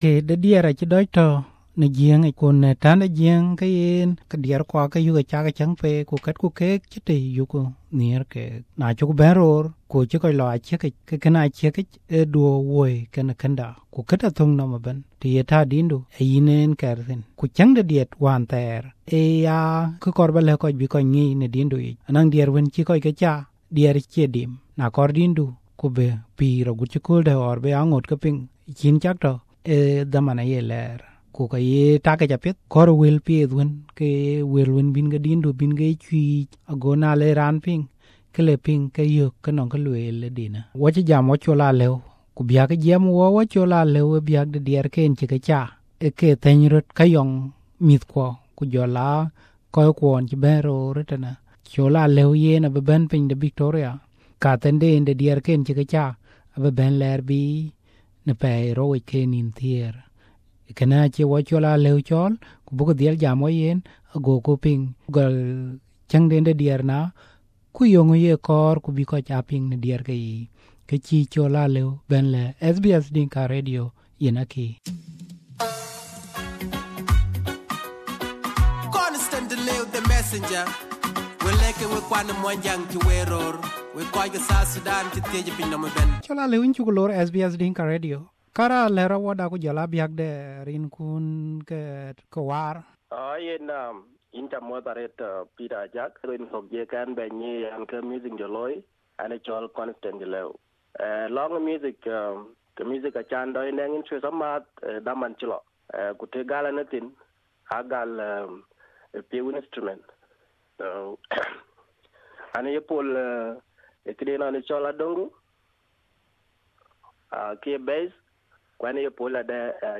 A Voice Through Music: Discussion